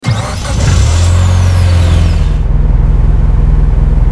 mitsuevox_idle.wav